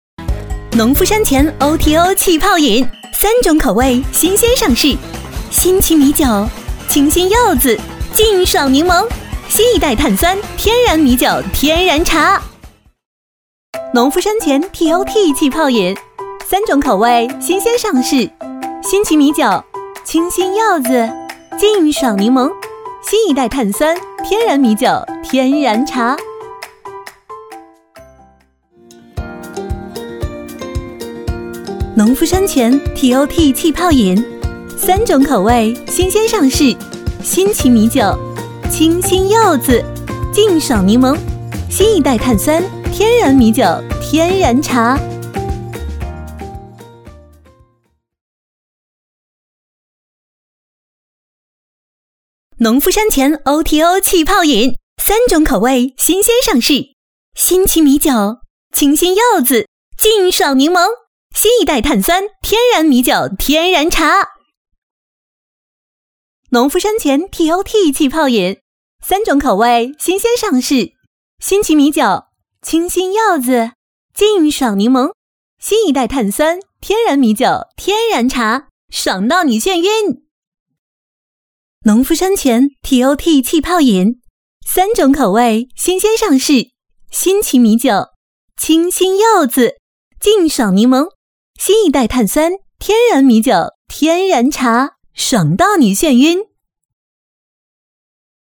女28-品牌广告-农夫山泉
女28知性大气 v28
女28-品牌广告-农夫山泉.mp3